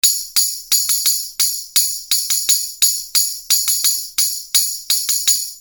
LP179 Cyclops Mountable Tambourine - Dimpled Brass Jingles, Black
• Model with dimpled brass jingles produces a distinct, crisp sound